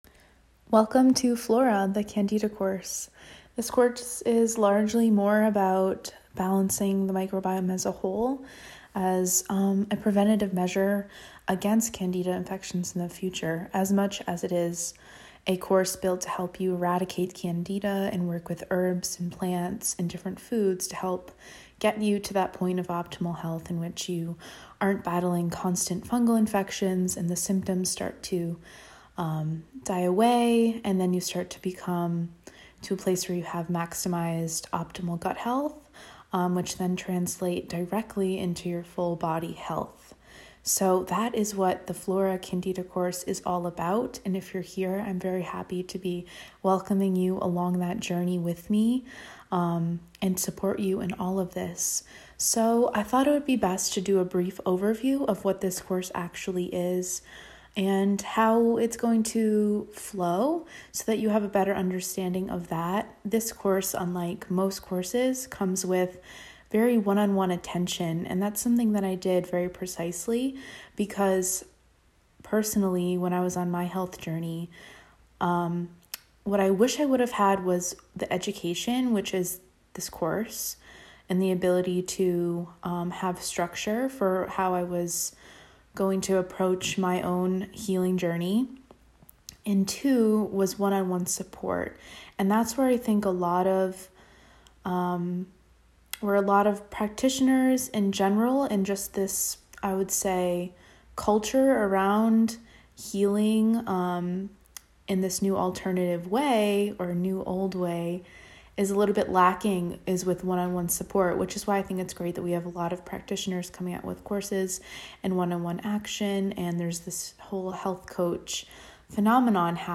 Audio Lesson: Welcome to Flora